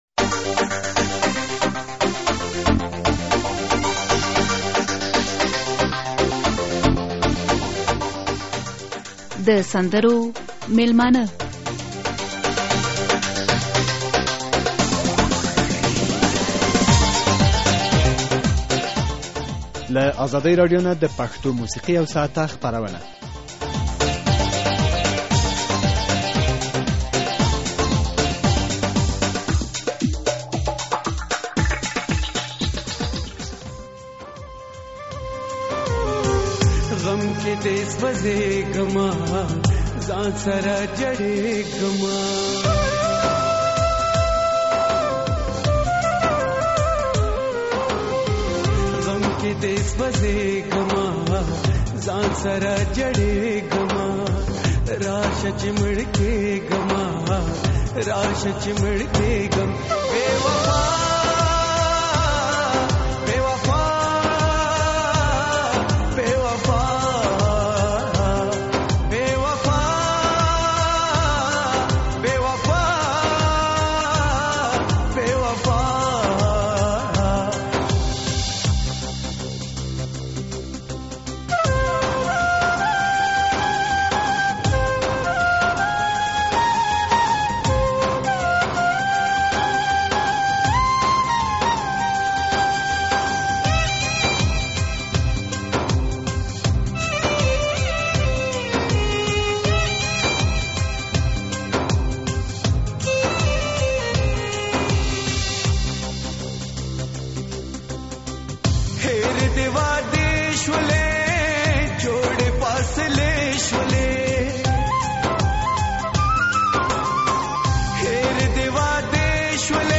د یکشنبې د ورځې سندریز پروګرام